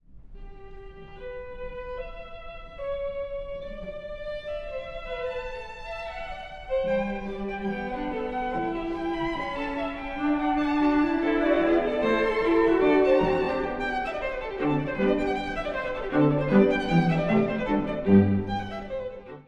第4楽章…宇宙のような立体感
4つの音が繰り返される、フーガ形式の終楽章。
複数のフガートが重なる時の立体感、構築美がすばらしいです。